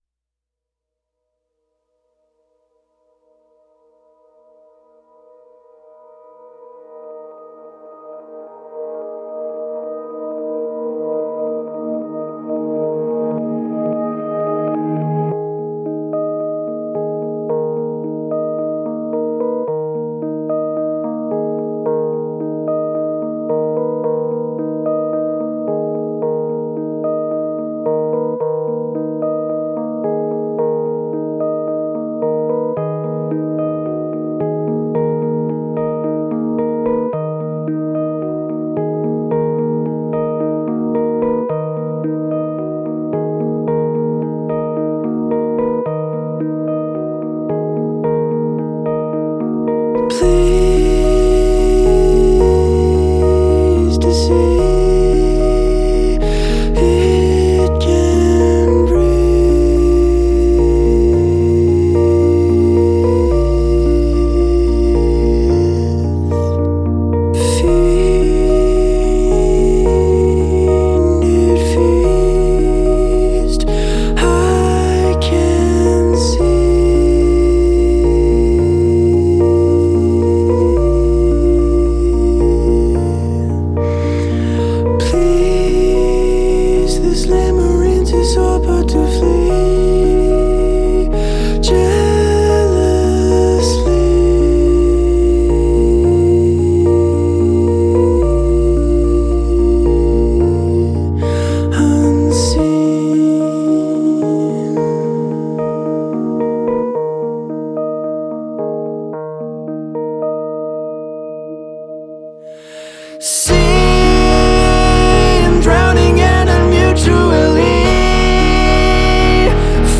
Indie Rock Alternative Rock